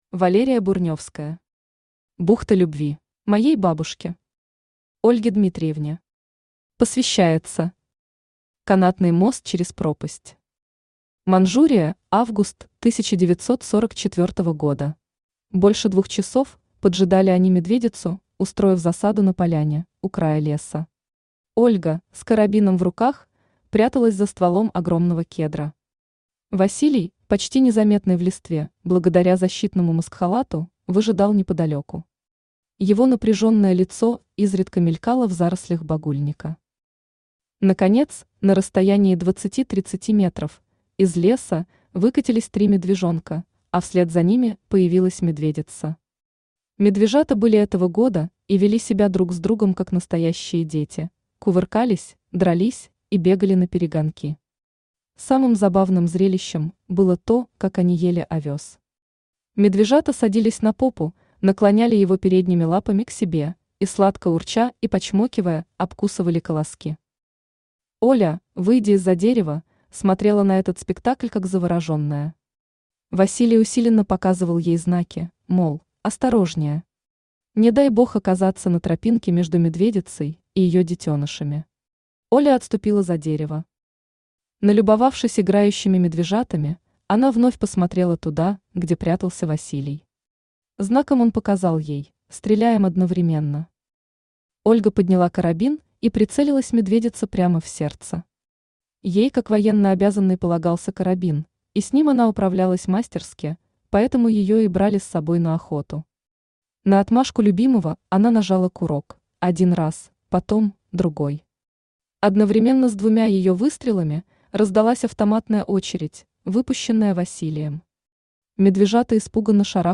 Аудиокнига Бухта любви | Библиотека аудиокниг
Aудиокнига Бухта любви Автор Валерия Бурневская Читает аудиокнигу Авточтец ЛитРес.